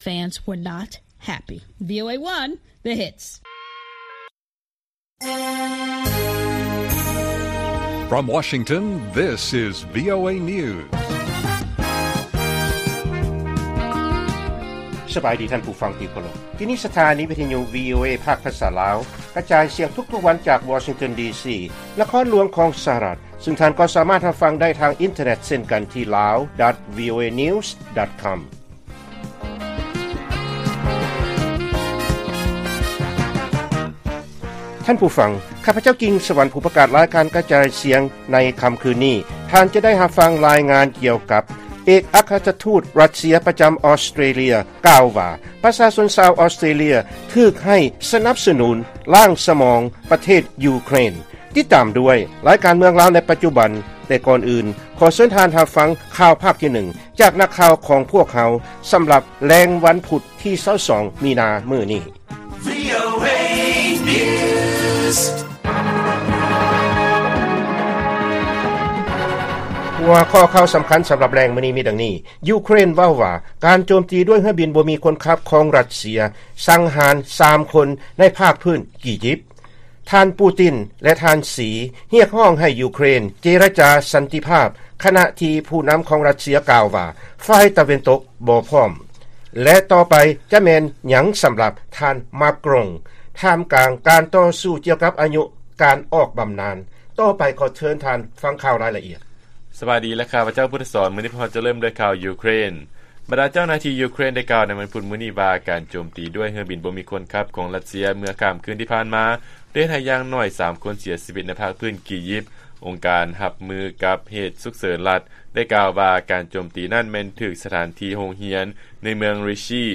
ລາຍການກະຈາຍສຽງຂອງວີໂອເອ ລາວ: ຢູເຄຣນ ເວົ້າວ່າການໂຈມຕີດ້ວຍເຮືອບິນບໍ່ມີຄົນຂັບຂອງ ຣັດເຊຍ ສັງຫານ 3 ຄົນ ໃນພາກພື້ນ ກີຢິບ